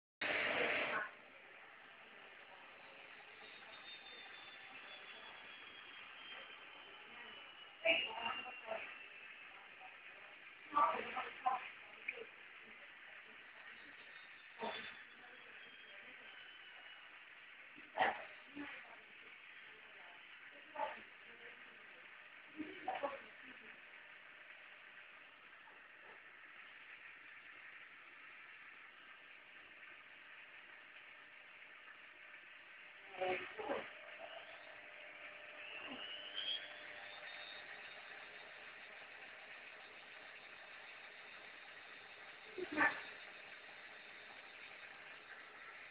In sostanza, sin da quando l'abbiamo comprata, emette un fischio assordante, tipo aereo in decollo!
Vi allego la registrazione del rumore che si sente mentre è in funzione (inizialmente il fischio non si sente perchè gira normalmente, ma poi (intorno ai 35 sec) comincia a girare un pò più velocemente e il fischio comincia!)
Scusate x (=per) i rumori di sottofondo ... !!! ;-)))
Rumore normalissimo perchè è una lavatrice con motore a collettore, il motore più comune utlizzato nelle lavatrici da una decina d'anni a questa parte, per non avere questo rumore dovevi acquistare una lavatrice con motore inverter che solitamente si trova solo in alcune lavatrici di alta gamma vendute a prezzi maggiori!
quel fischio che si sente nella registrazione si sente quando gira lentamente (non con la centrifuga)!
rumore Candy Go 106 df.mp3